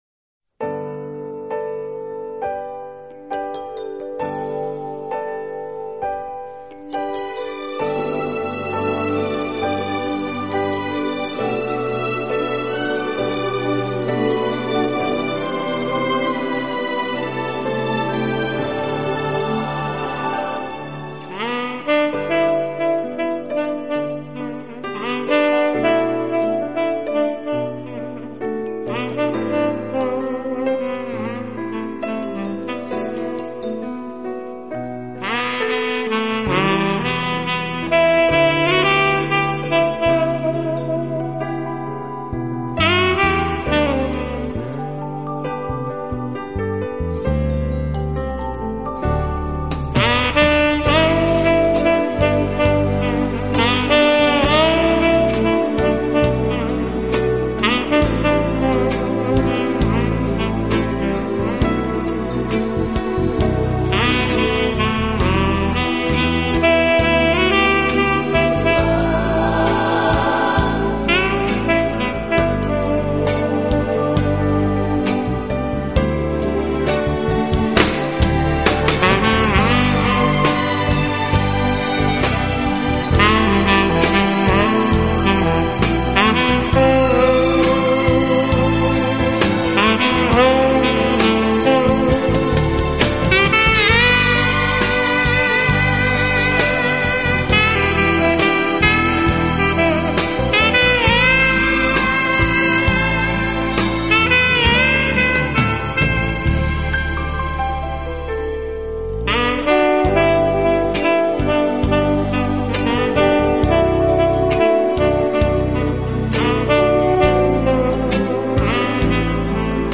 轻快、柔和、优美，带有浓郁的爵士风味。